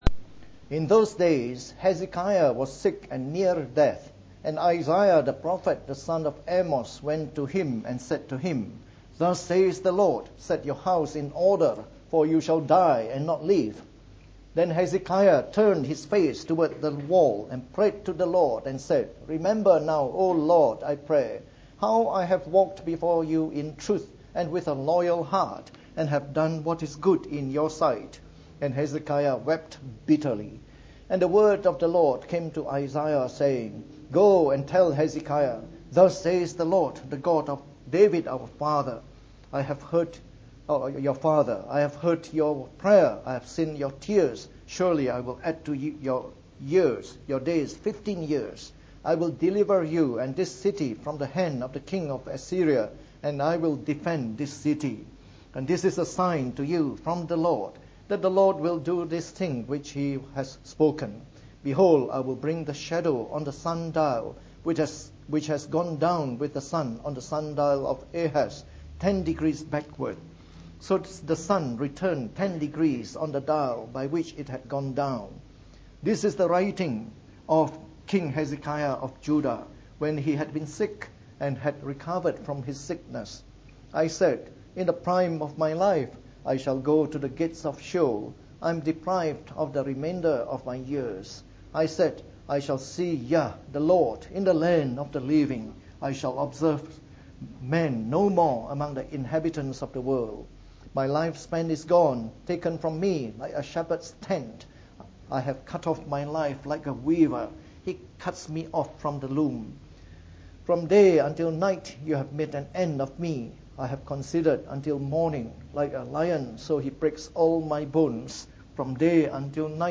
From our series on the Book of Isaiah delivered in the Morning Service.